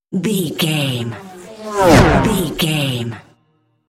Pass by fast speed engine
Sound Effects
Fast
pass by
car
vehicle